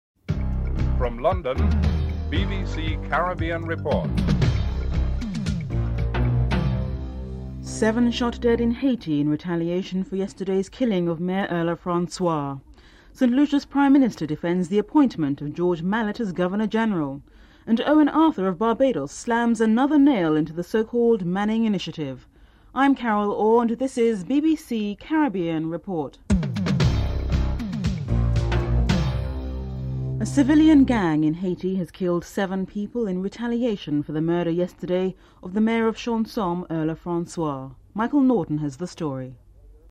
1. Headlines (00:00-00:28)
Finance Minister Bharat Jagdeo is interviewed (12:28-13:14)